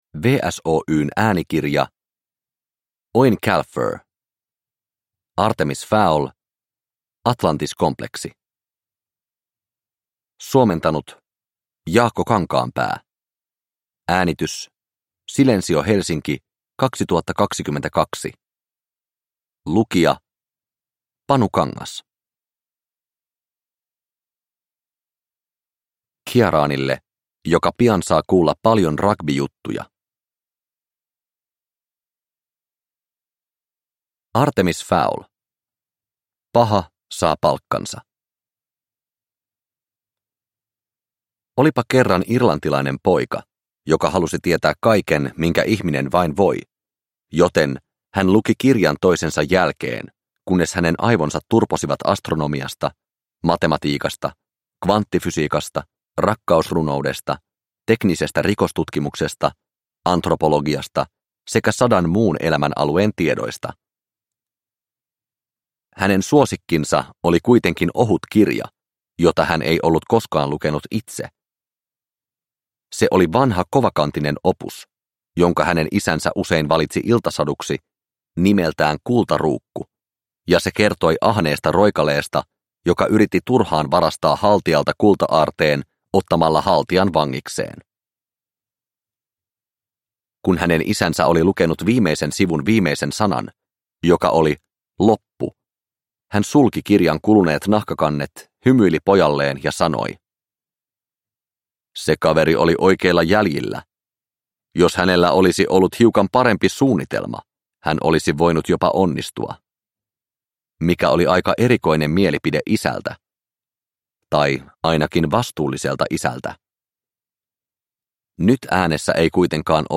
Artemis Fowl: Atlantiskompleksi – Ljudbok – Laddas ner